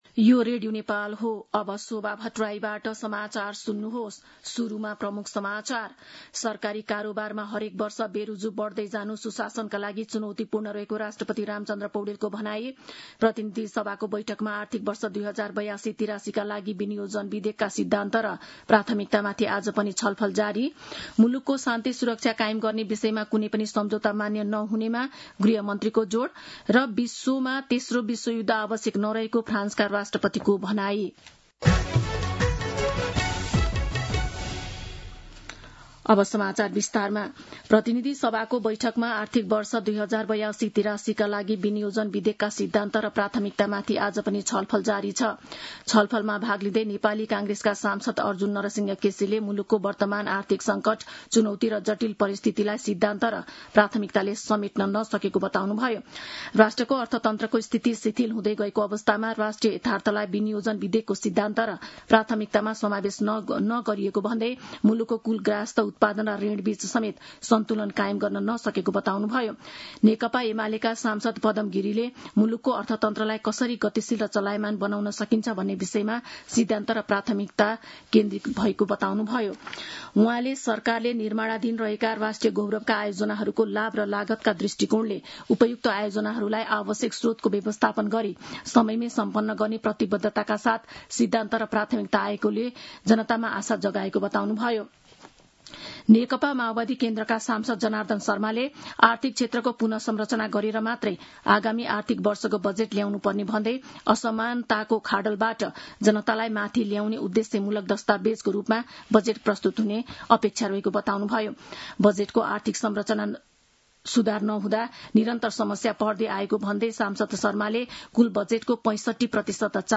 दिउँसो ३ बजेको नेपाली समाचार : ३१ वैशाख , २०८२
3-pm-news.mp3